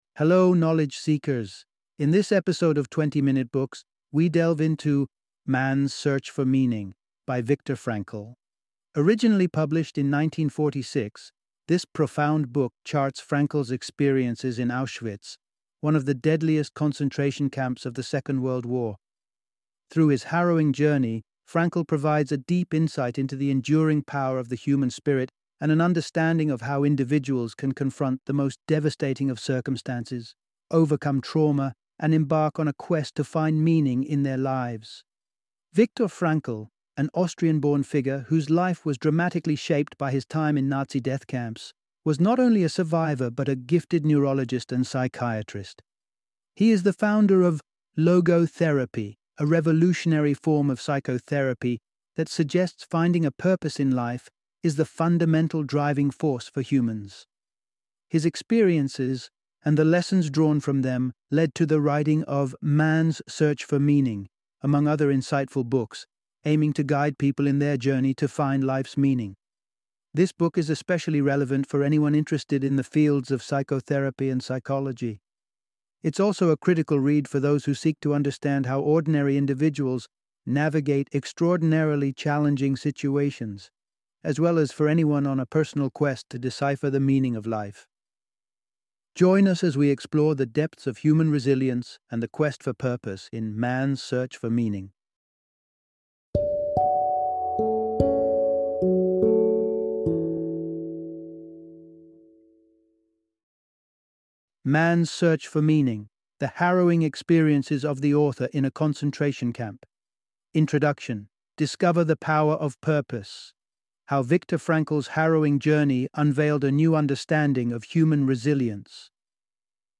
Man’s Search for Meaning - Audiobook Summary